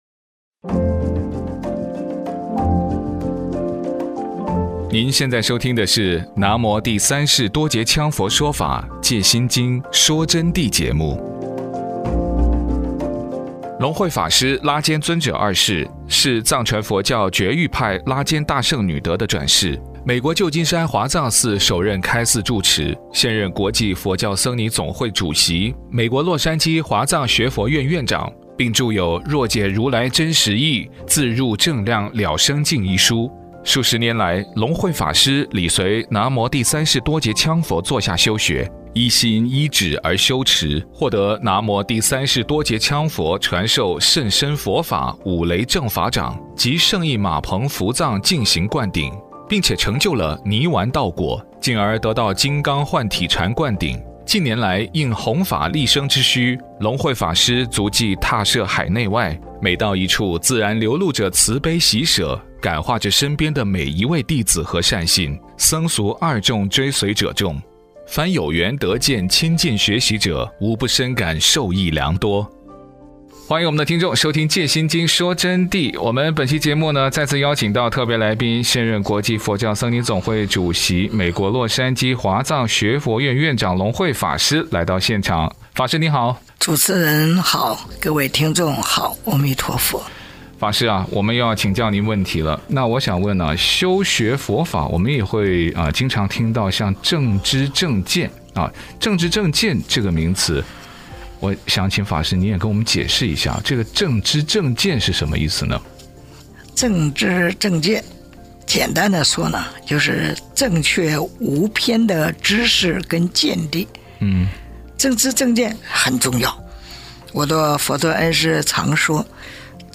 佛弟子访谈（三十九）什么是正知正见？佛陀亲说法音有多稀有难得？